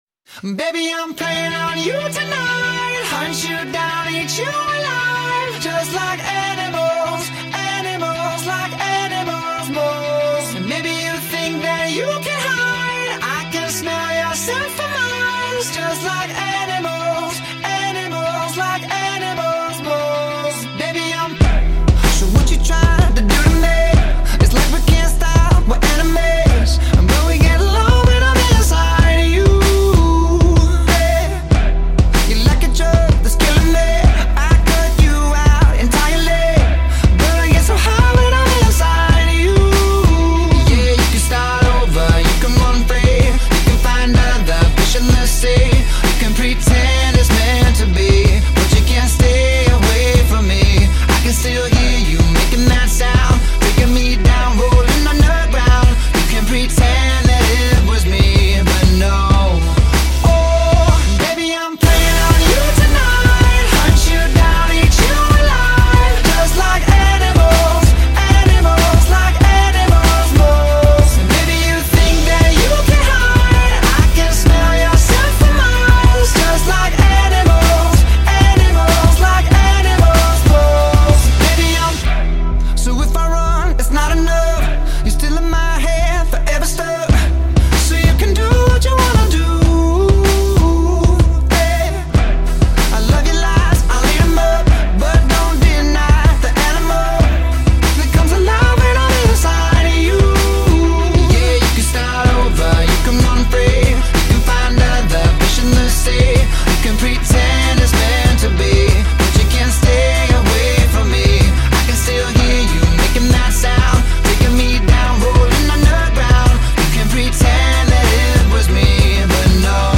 Pop 2010er